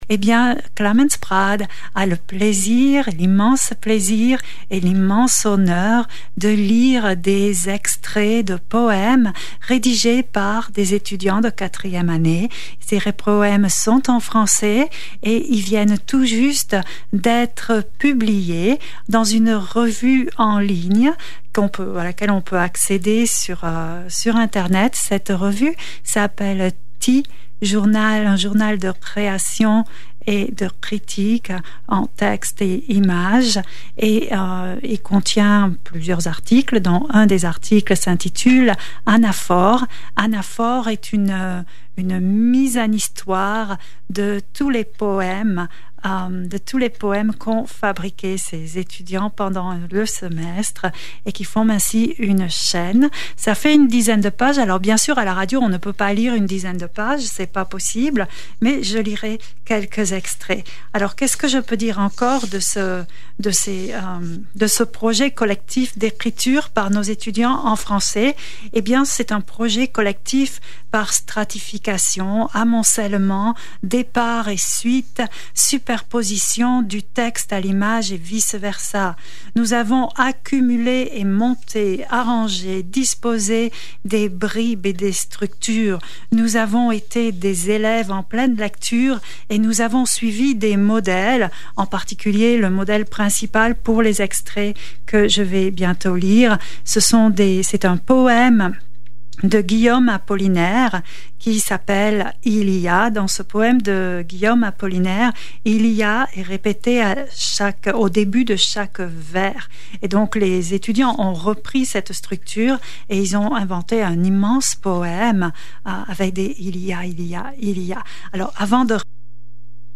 Nos étudiant-e-s à la radio